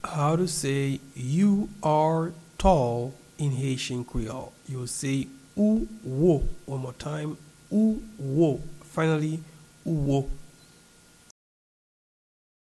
Pronunciation and Transcript:
You-are-tall-in-Haitian-Creole-Ou-wo-pronunciation.mp3